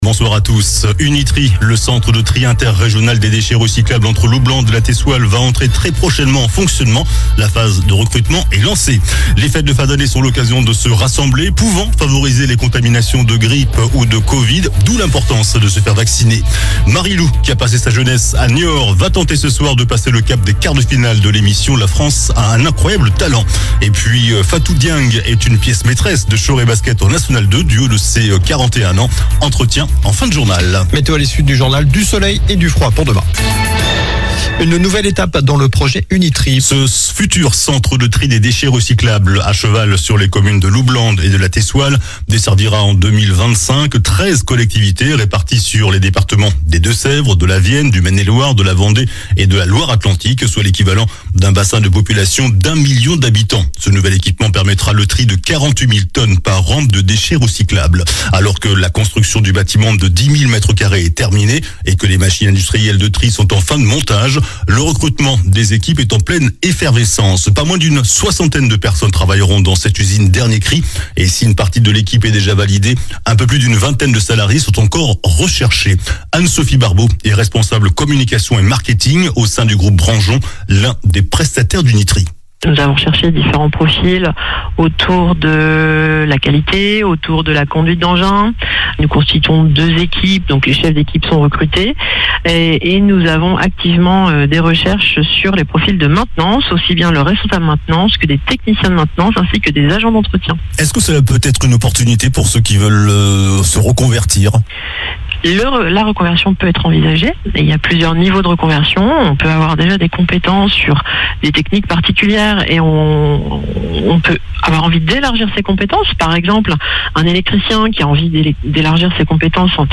Journal du mercredi 11 décembre (soir)